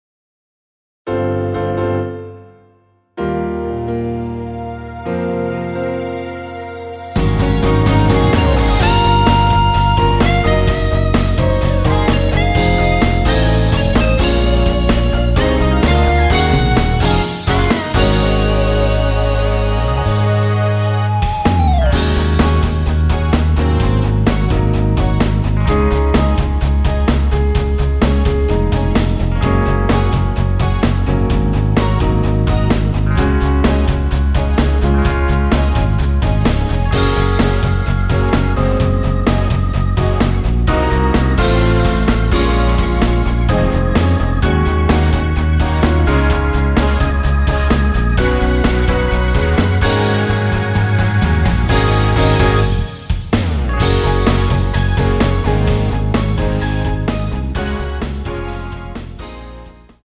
전주 없이 시작 하는곡이라 전주 만들어 놓았습니다.(미리듣기 참조)
Db
앞부분30초, 뒷부분30초씩 편집해서 올려 드리고 있습니다.
중간에 음이 끈어지고 다시 나오는 이유는